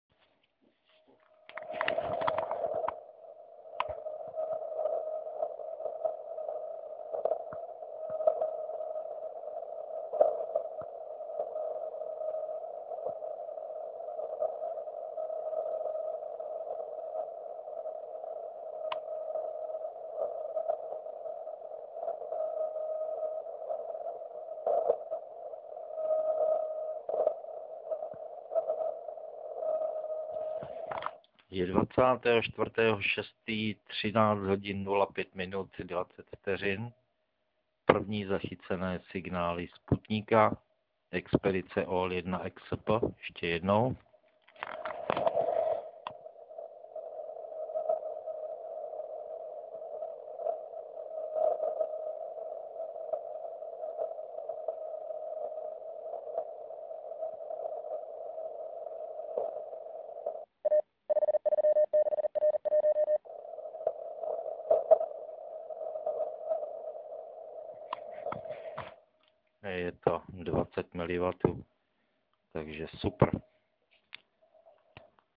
Vysílal zkušebně na 3536.1 KHz.
V Liberci:
Zde si všimněte, že šlo o typické šíření via Es vrstvu.
Útlum od Déčka musí být obrovský, přesto i těchto 20mW je jasně identifikovatelné.